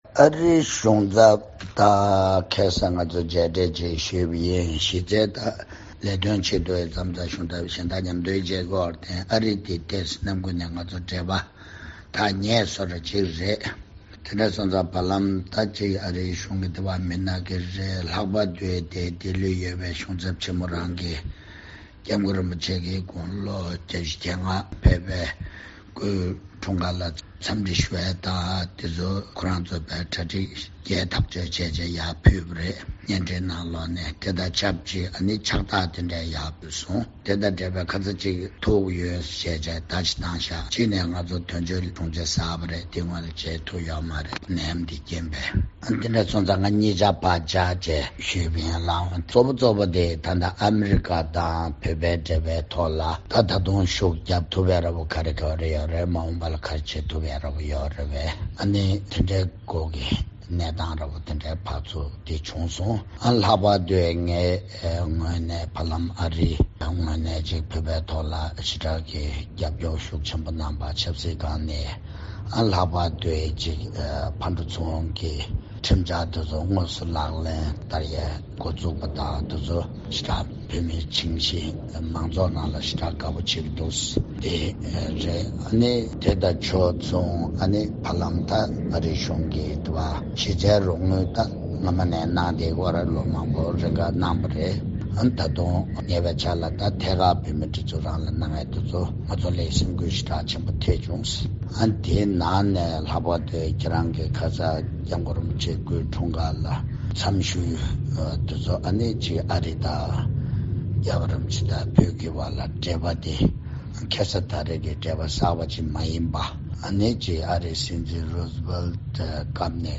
མཇལ་འཕྲད་དེའི་སྐོར་ལྡི་ལི་སྐུ་ཚབ་དོན་གཅོད་བཀའ་ཟུར་འབྲོང་ཆུང་དངོས་གྲུབ་ལགས་སུ་གནས་འདྲི་ཞུས་པ་གསན་རོགས།
སྒྲ་ལྡན་གསར་འགྱུར། སྒྲ་ཕབ་ལེན།